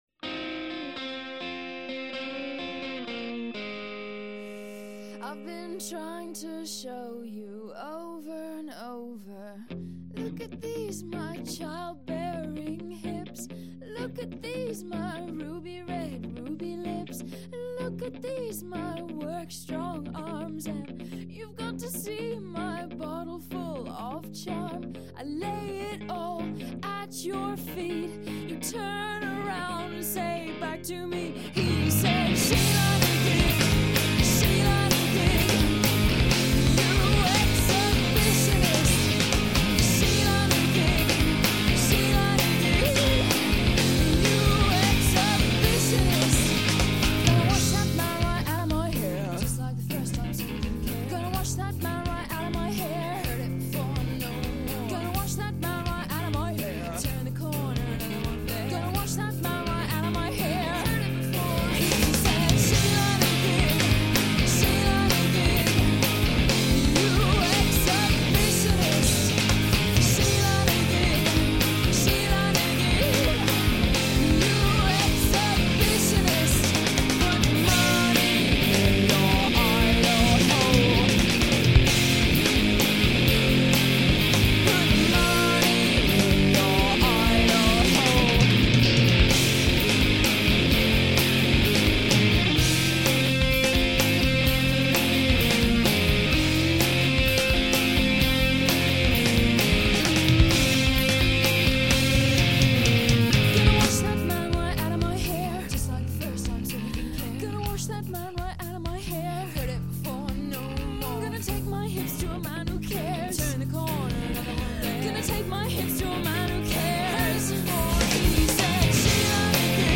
ook hier valt de autonome stem op